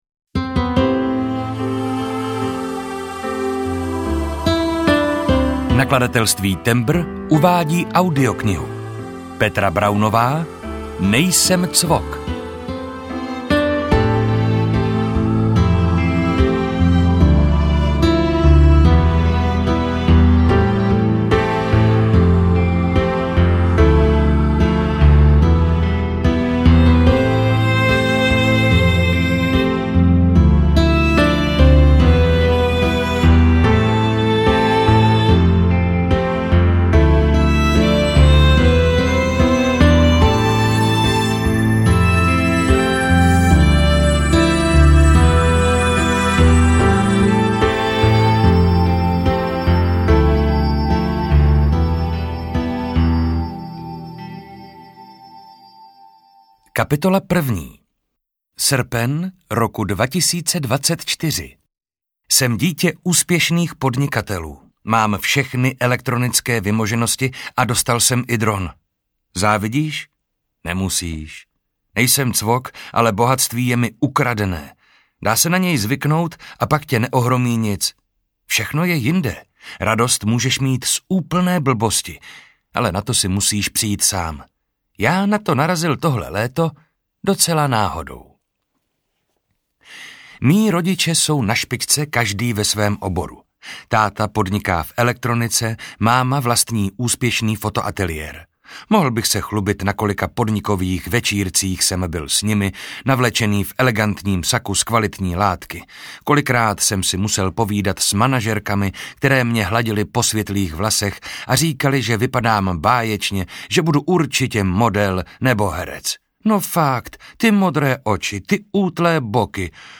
Nejsem cVok audiokniha
Ukázka z knihy